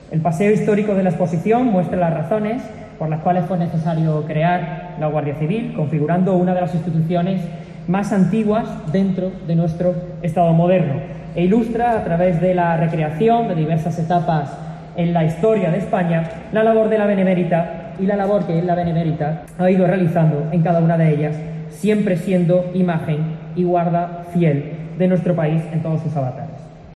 Escucha al secretario de Estado de Seguridad, Rafael Pérez